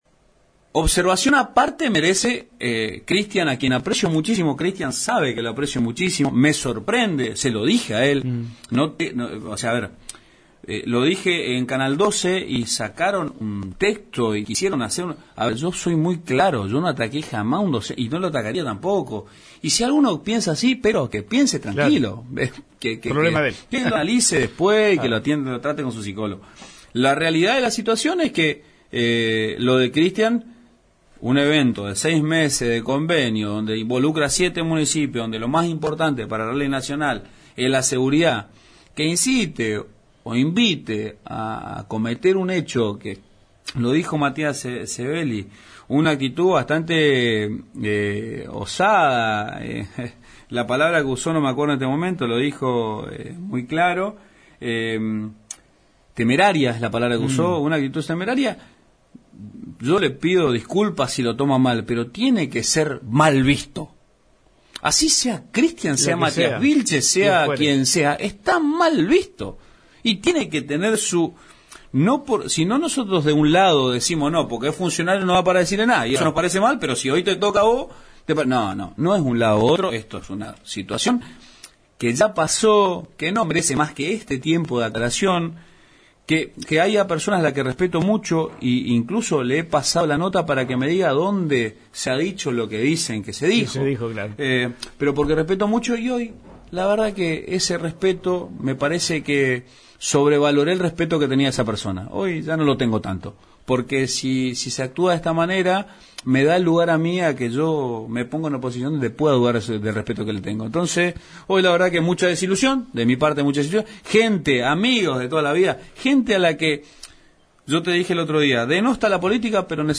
En una entrevista con FM Alto Uruguay, el intendente de San Javier, Matías Vilchez, abordó el tenso momento que precedió a la cuarta fecha del Rally Nacional, marcado por un intento de boicot.